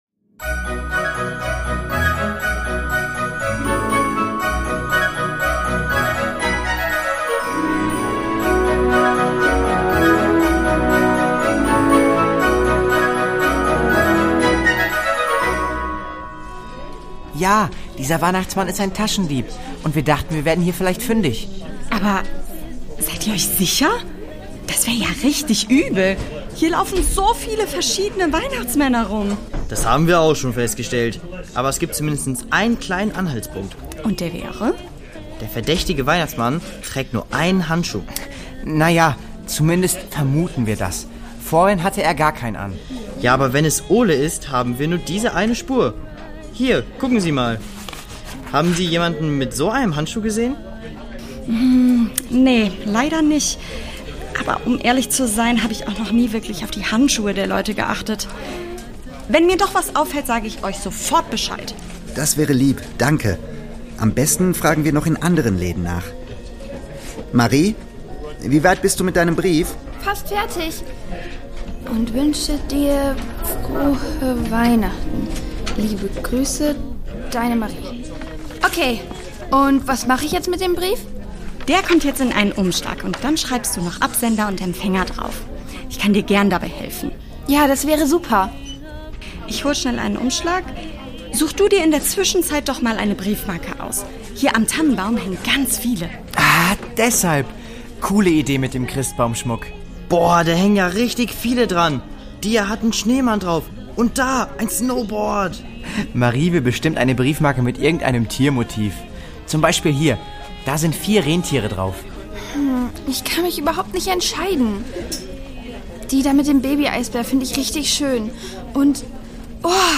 Die Doppeldecker Crew | Hörspiel für Kinder (Hörbuch)